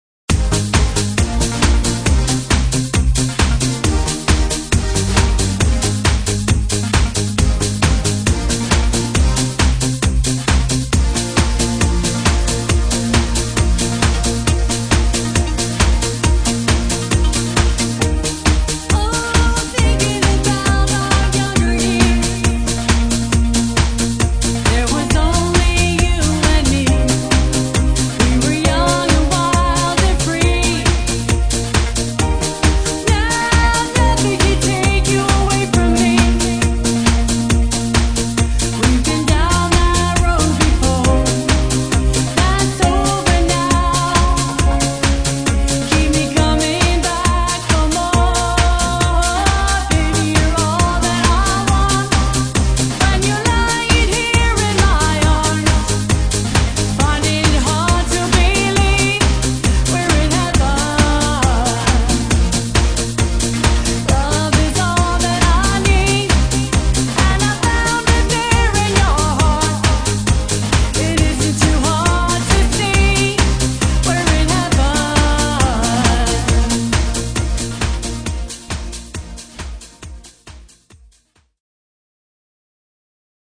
Dance/Top 40